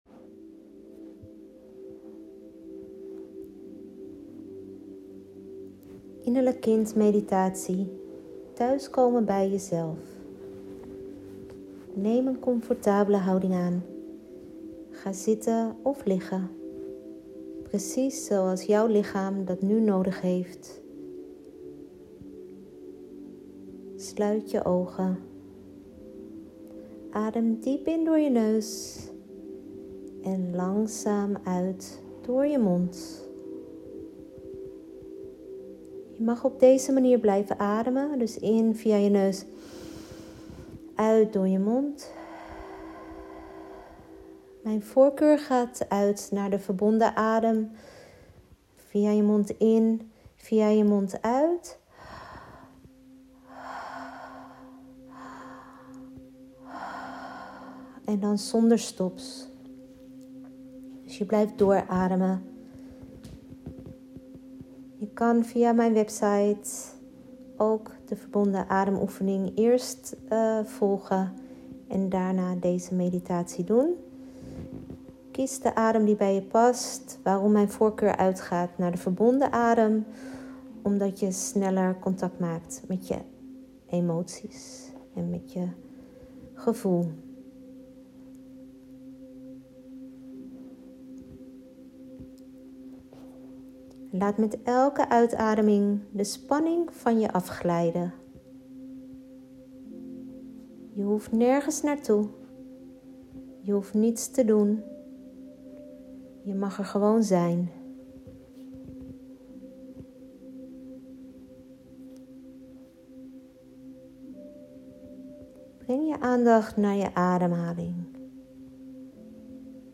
Het enige wat je hoeft te doen, is mijn stem volgen en je overgeven aan de meditatie of ademhalingsoefening.